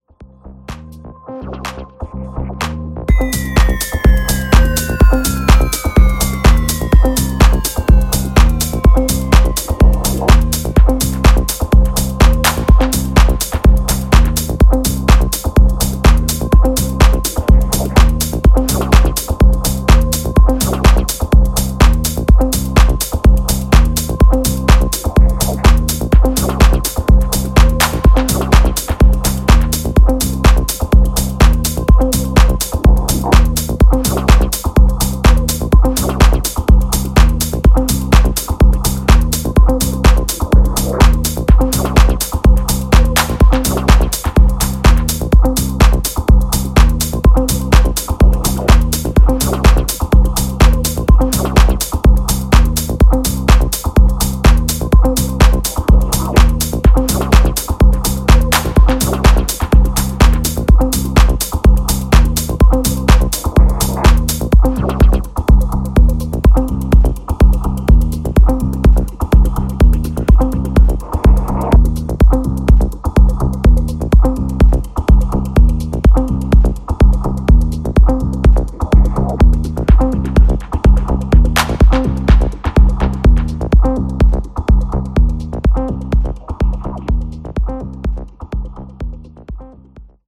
ジャンル(スタイル) TECH HOUSE